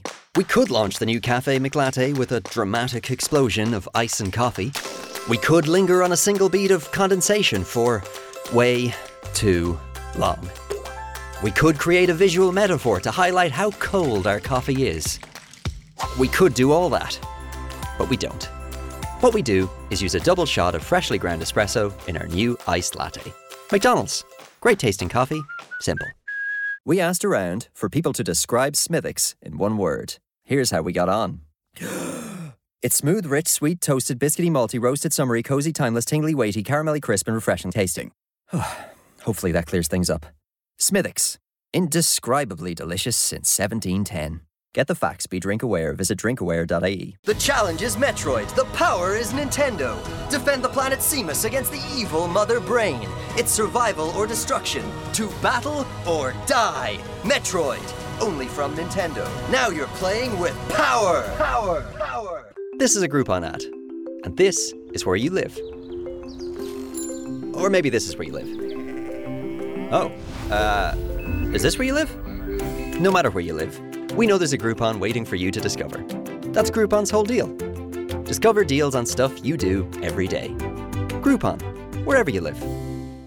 Voice samples
He has voiced campaigns across tech, finance, and food, with a tone that suits both conversational and polished briefs.